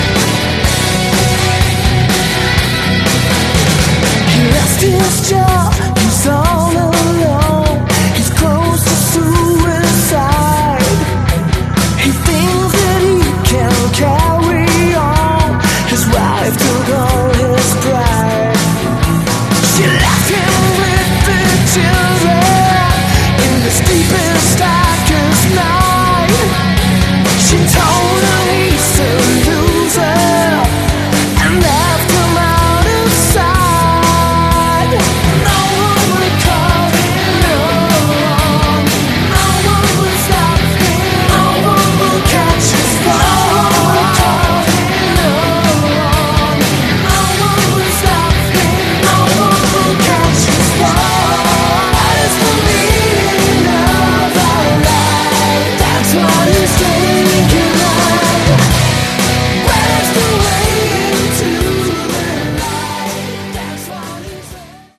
Category: AOR
Vocals
Guitar, Keyboards
Bass
Drums